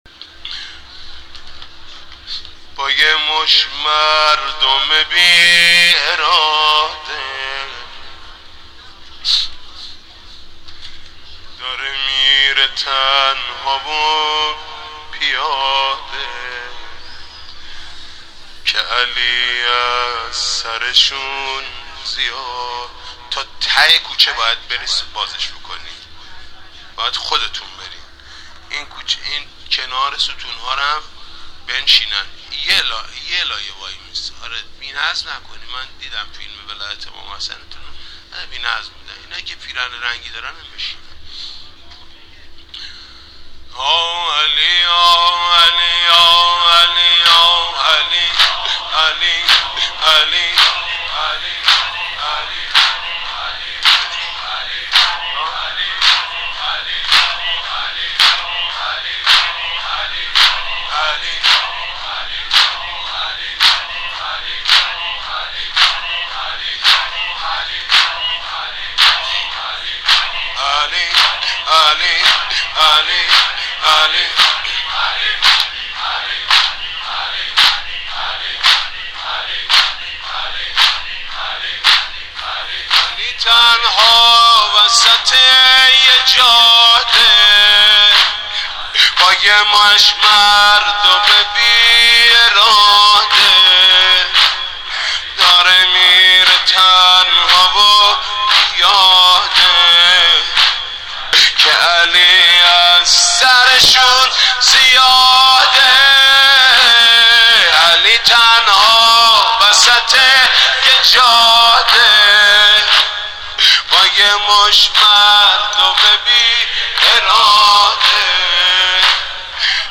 حاج محمود کریمی/شب نوزدهم ماه مبارک رمضان(95)